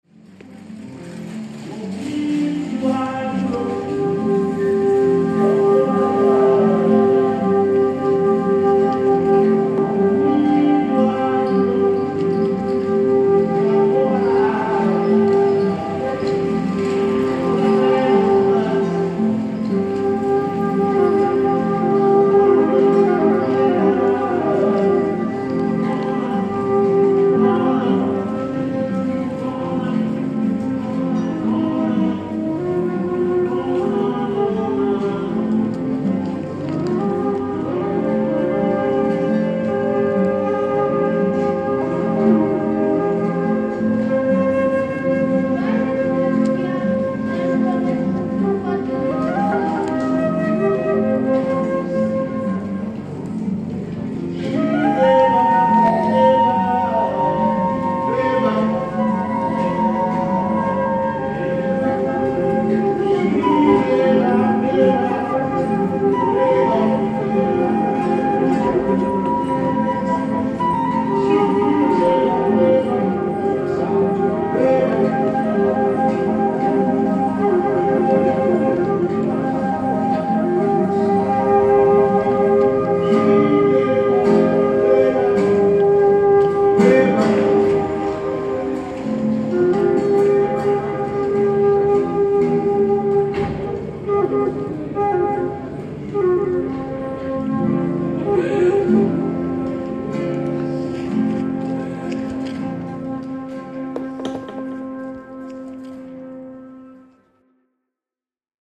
Padova busker recording reimagined